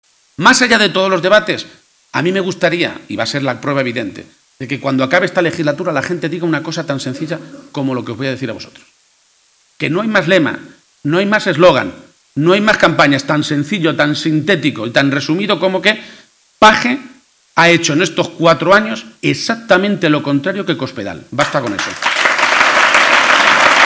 Durante la inauguración del Congreso Provincial del PSOE de Cuenca, que se celebra este fin de semana
Cortes de audio de la rueda de prensa